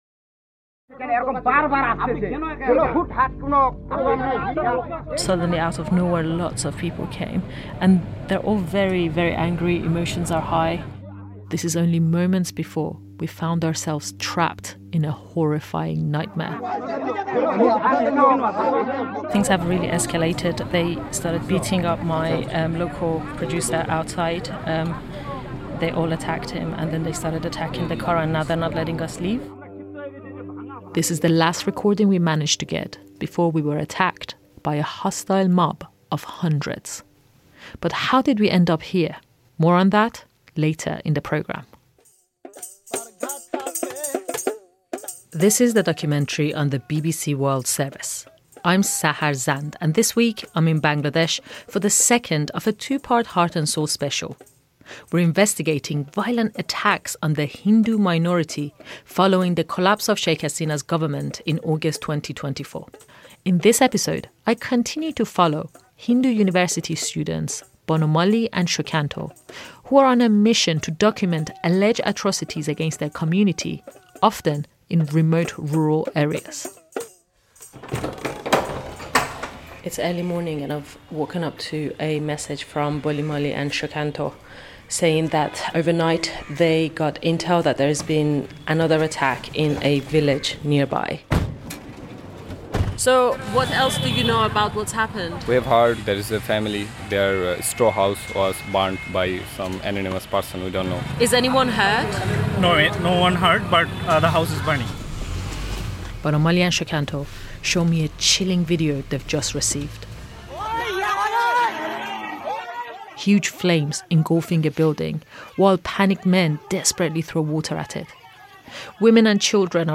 The documentary then reveals that the attacks aren’t just aimed at buildings.